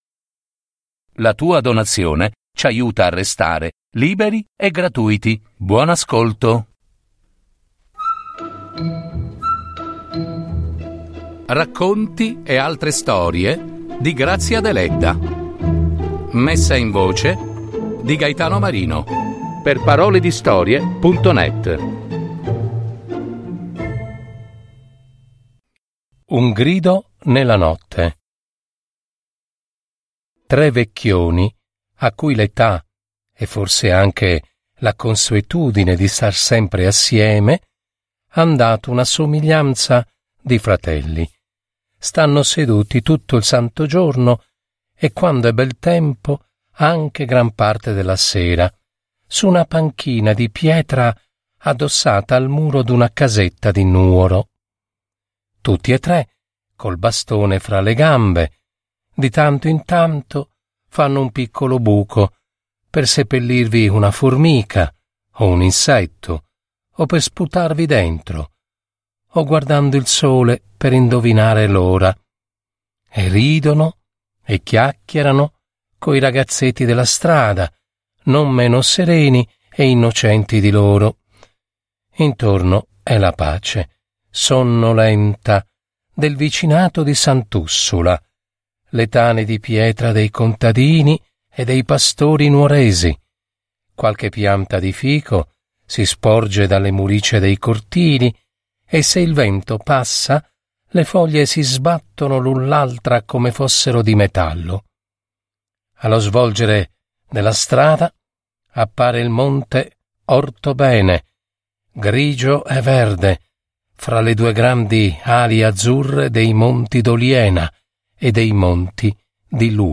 Messa in voce